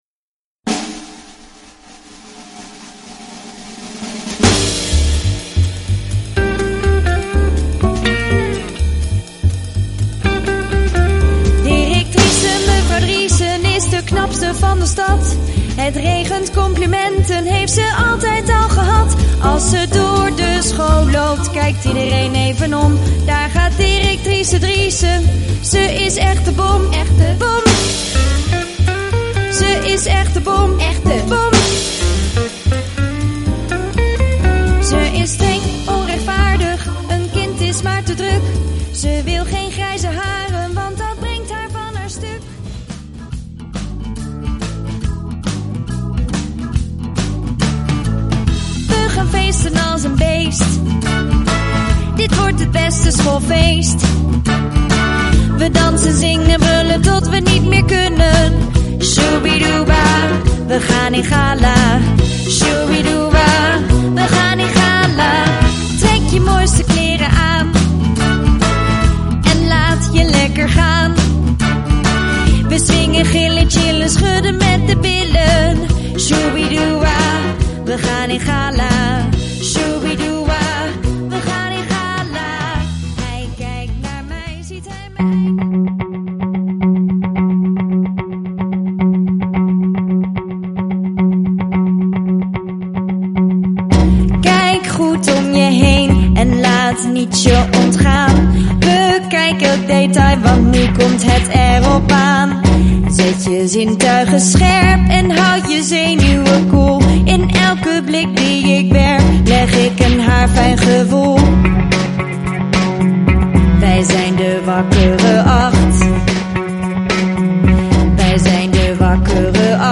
Dit is een muziekfragment van de liedjes.